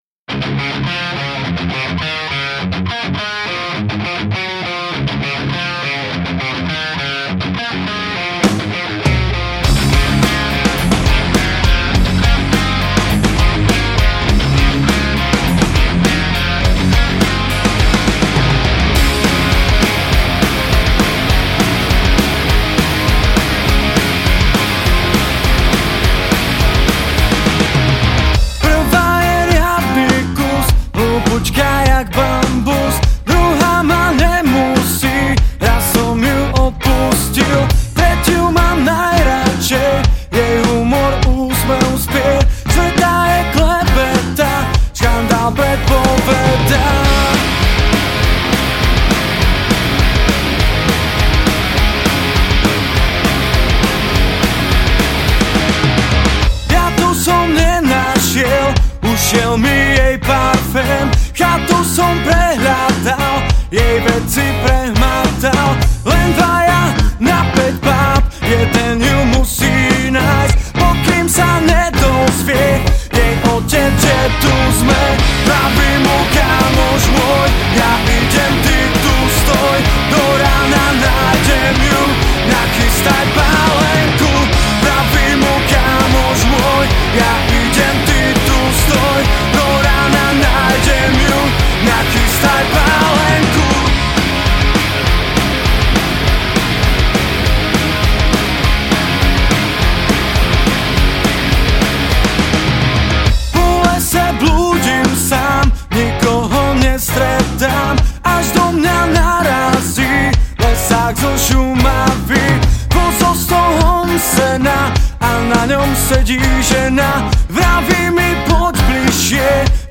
Žánr: Rock
gitara, spev
basgitara, spev
bicie, spev